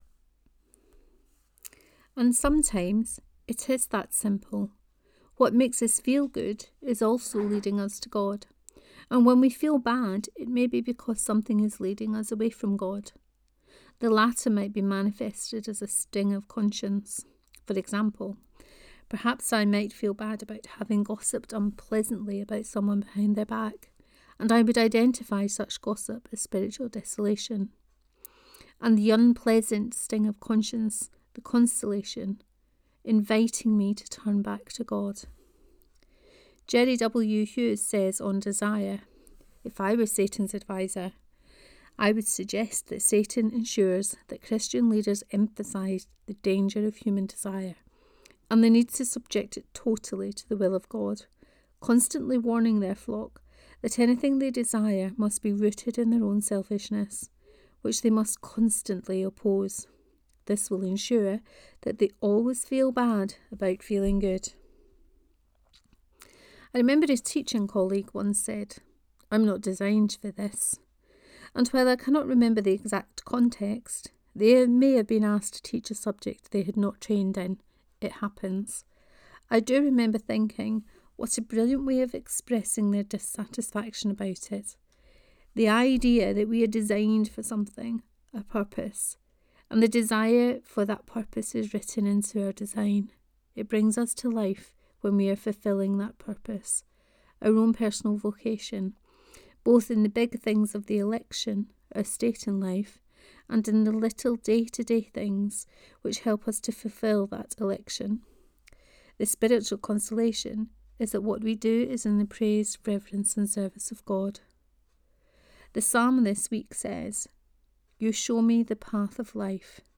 What brings you to life 3: Reading of this post.